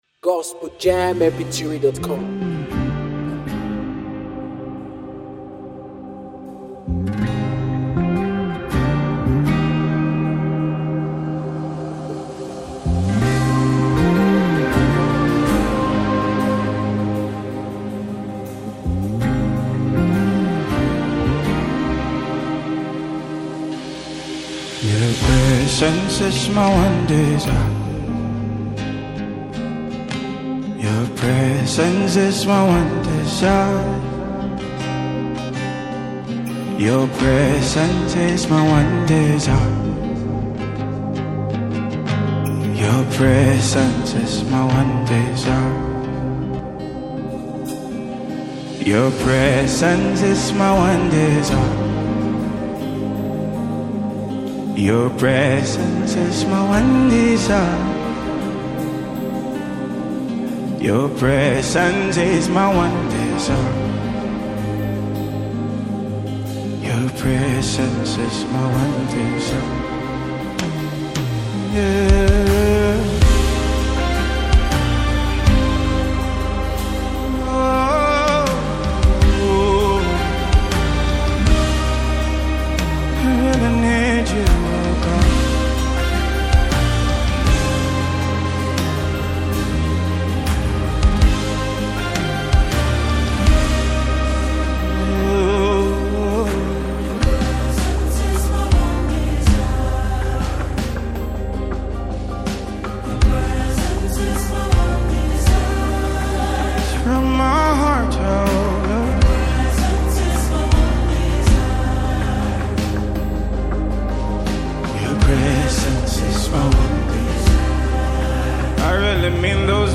powerful and soulful gospel worship song